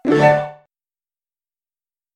Быстрый и эффективный